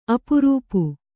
♪ apurūpu